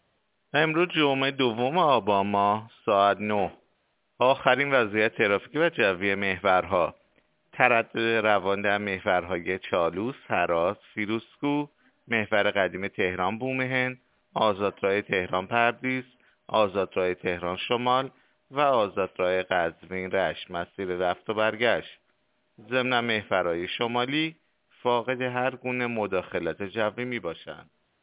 گزارش رادیو اینترنتی از آخرین وضعیت ترافیکی جاده‌ها ساعت ۹ دوم آبان؛